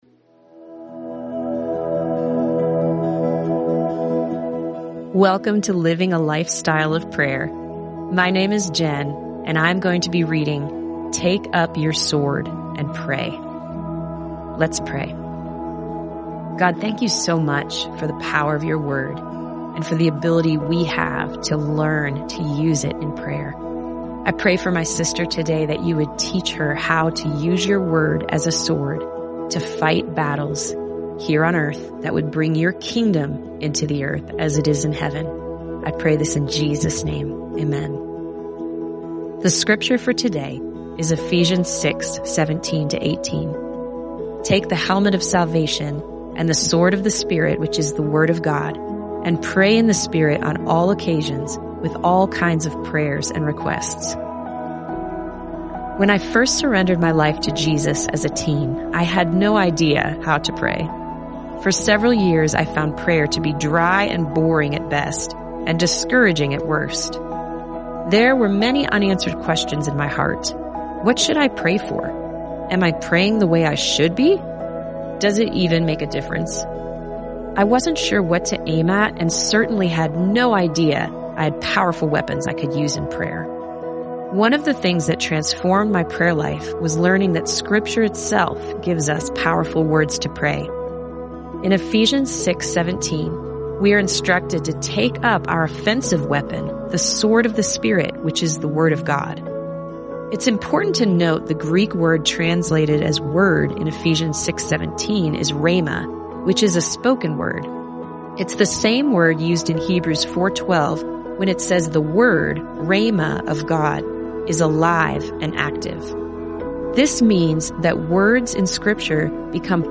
Living a Lifestyle of Prayer: 30-Day Audio Devotional for Moms